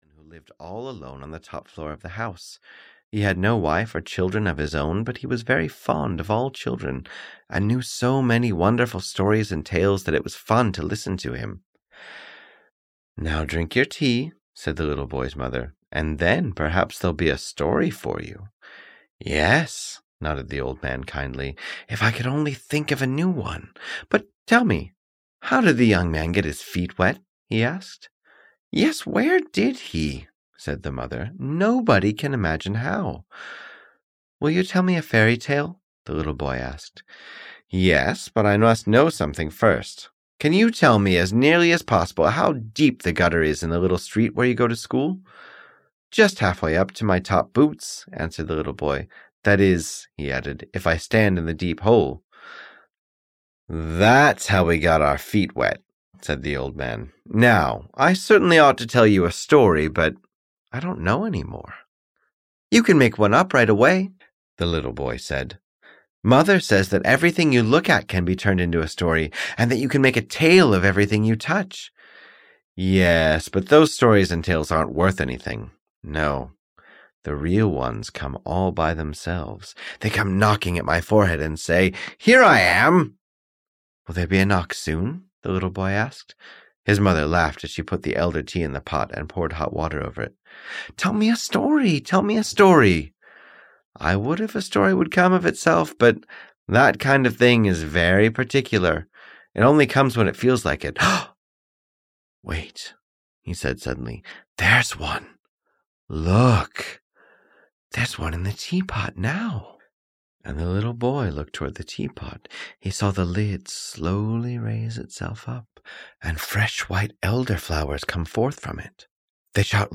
The Elder-Tree Mother (EN) audiokniha
Ukázka z knihy